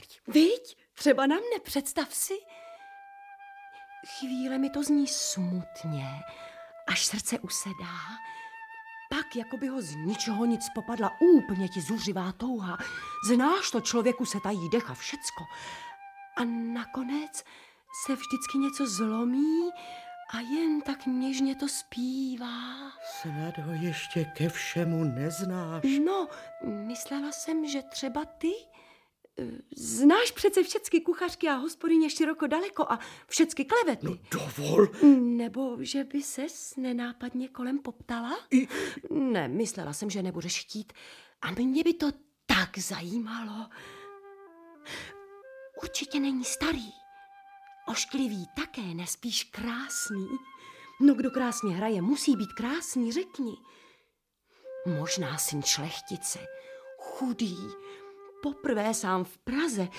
Audiobook
Read: Antonie Hegerlíková